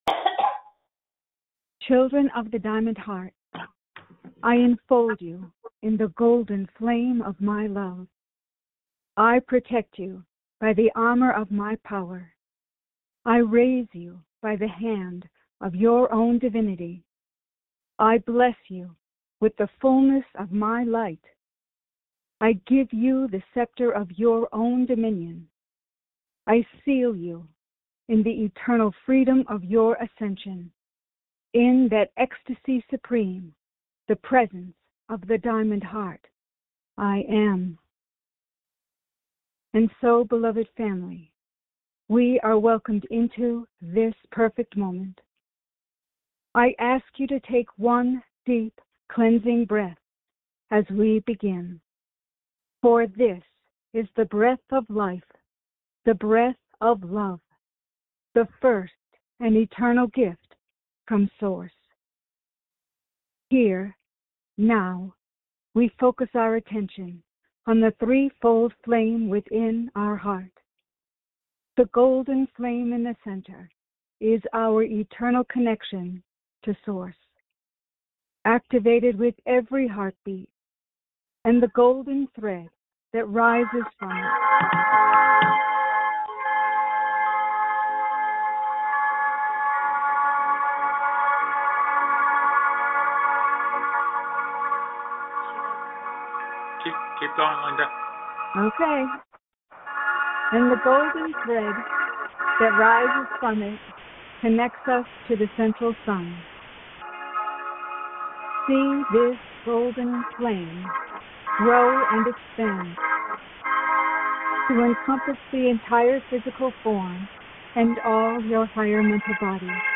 Follow along in group meditation with Master Saint Germain